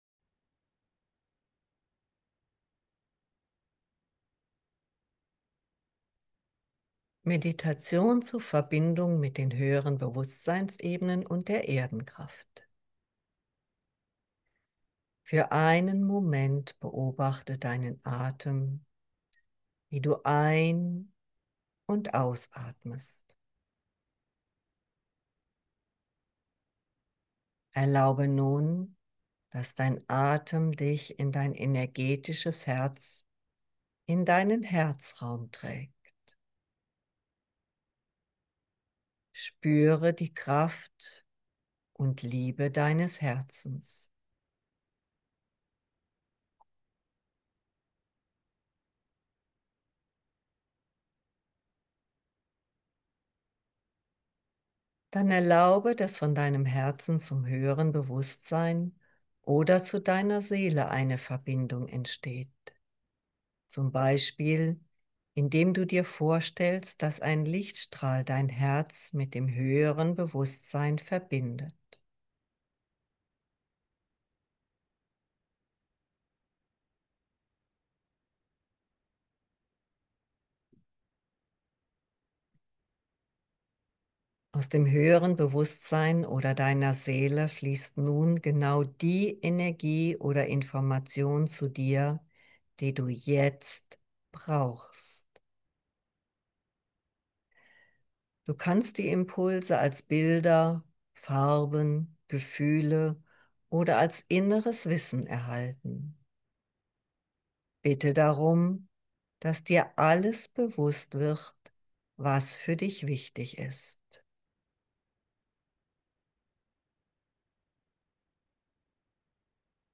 Kurzmeditationen